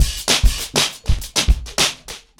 PrintOuts-100BPM.1.wav